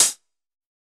UHH_ElectroHatD_Hit-02.wav